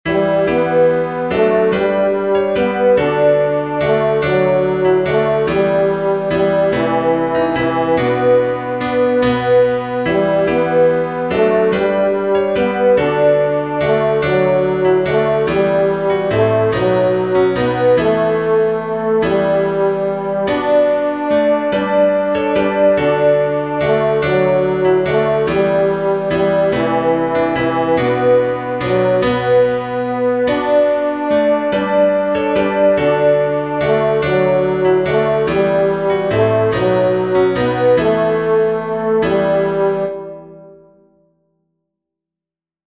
what_child_is_this-tenor.mp3